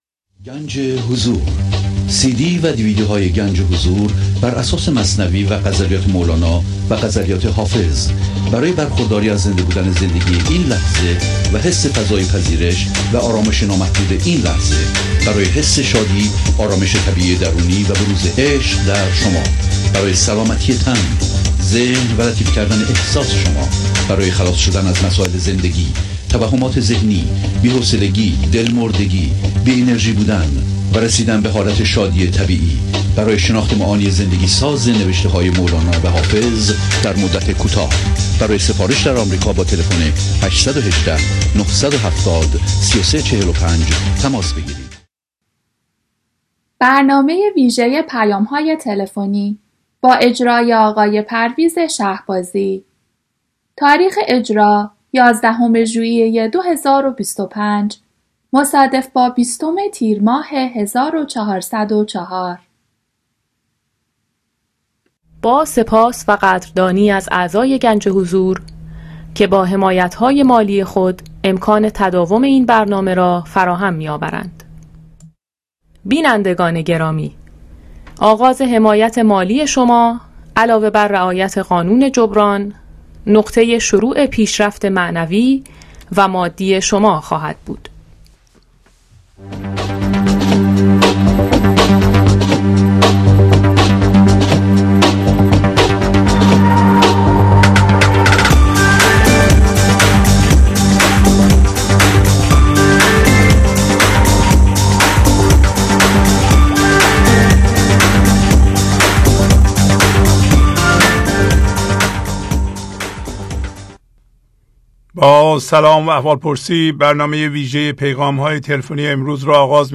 Ganj e Hozour Telephone messages